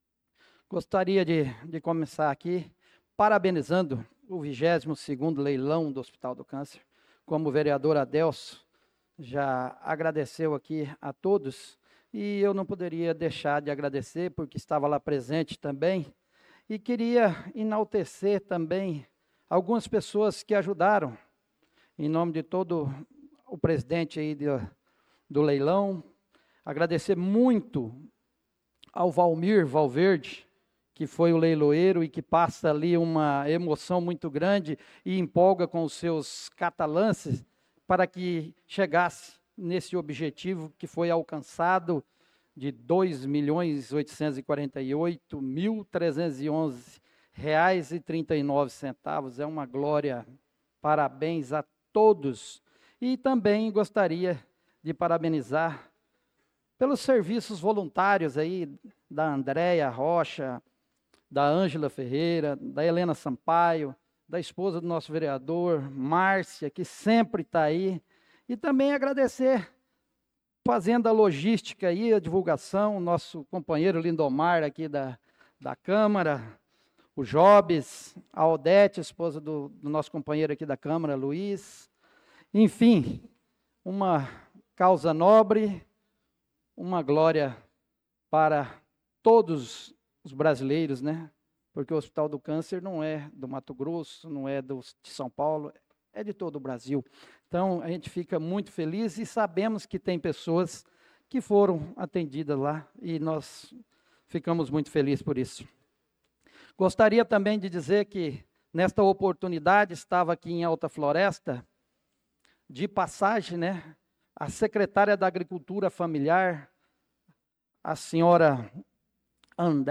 Pronunciamento do vereador Marcos Menin na Sessão Ordinária do dia 04/08/2025.